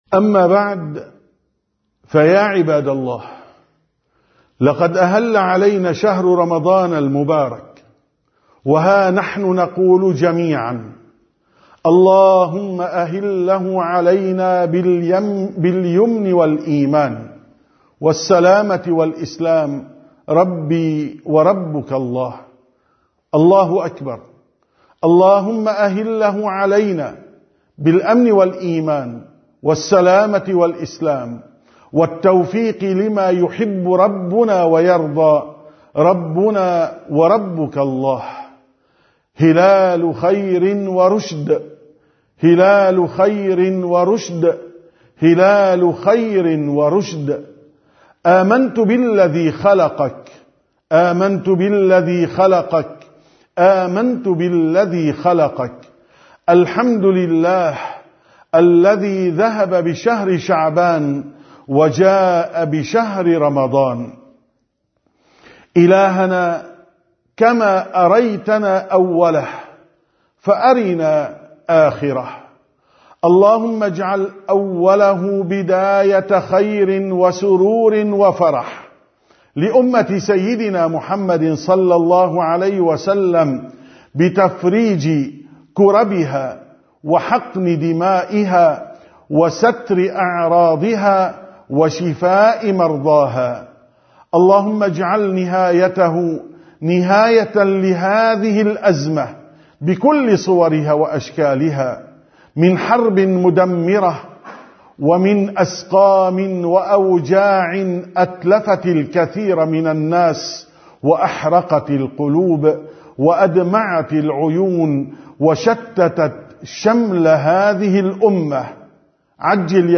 703ـ خطبة الجمعة: يا من طالت غيبته وخسارته